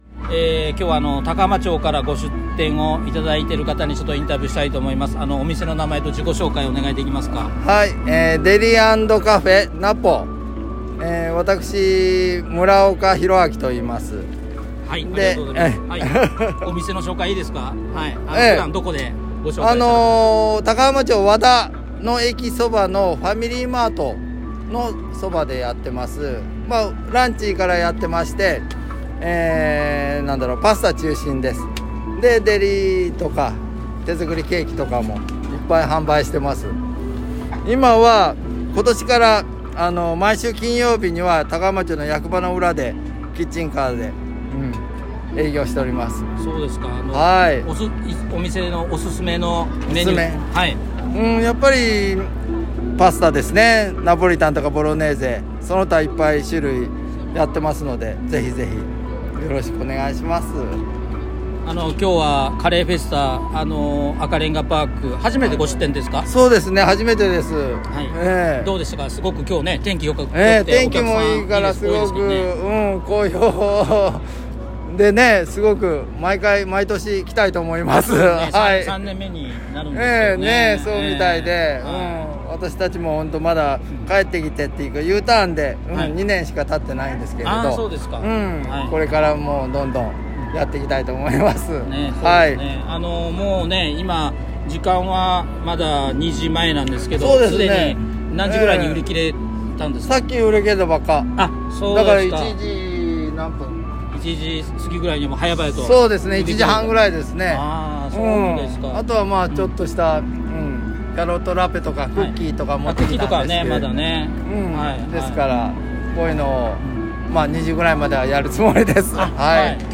番組名：【福井県高浜町】FMまいづる77.5MHz Marine Station Kyoto 公開生放送
▼高浜町和田のカフェ「Deli＆Cafe NaPo」さんのインタビュー